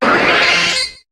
Cri de Stari dans Pokémon HOME.